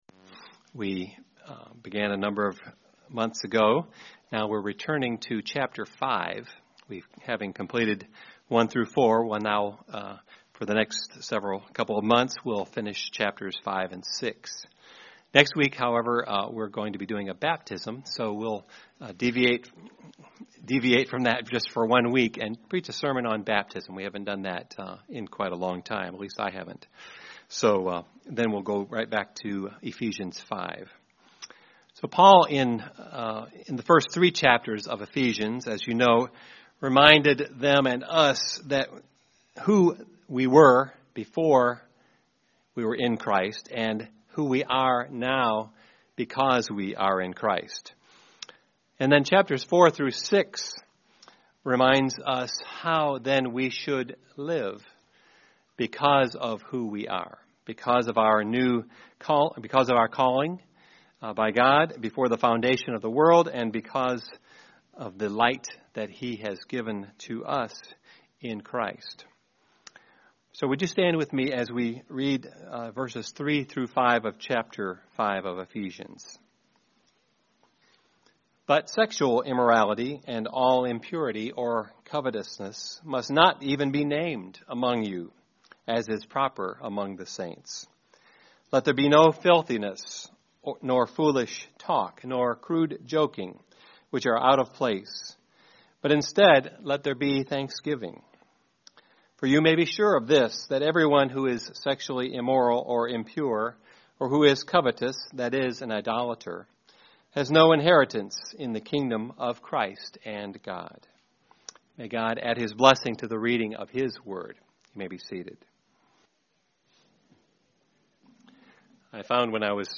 Intro to sermon